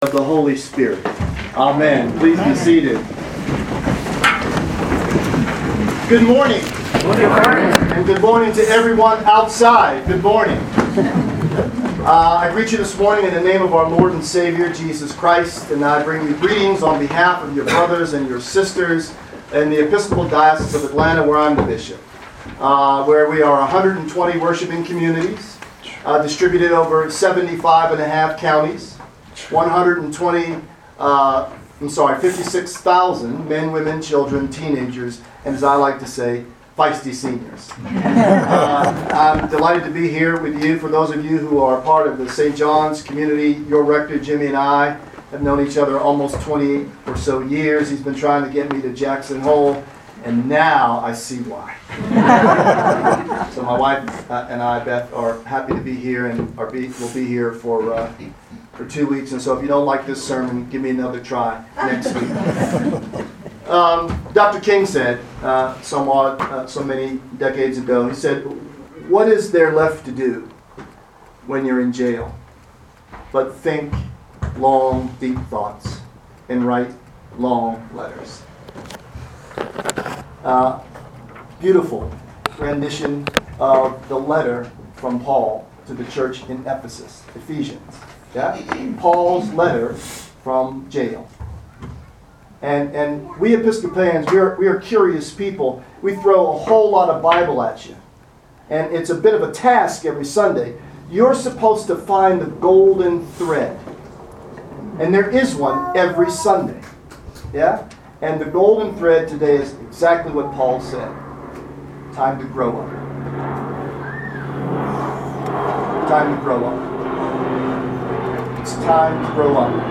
Sermons from St. John's Episcopal Church Chapel of the Transfiguration Proper 13 - The Rt.